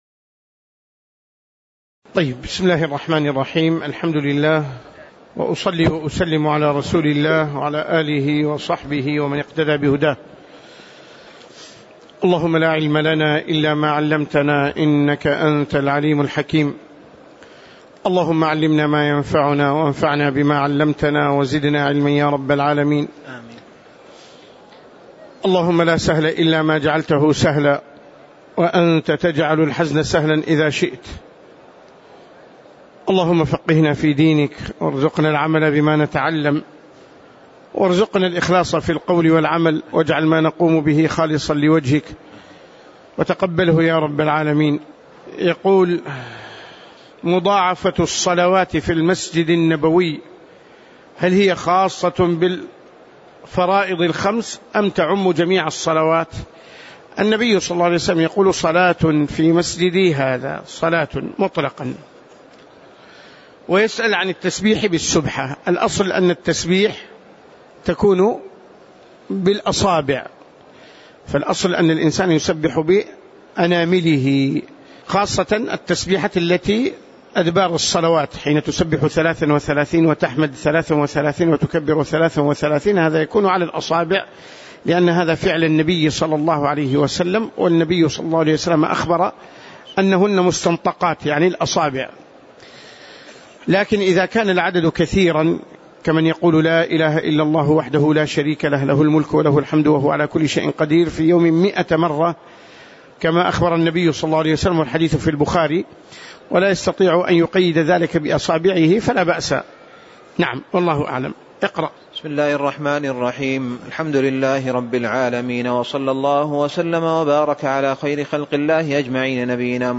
تاريخ النشر ٢٨ شعبان ١٤٣٧ هـ المكان: المسجد النبوي الشيخ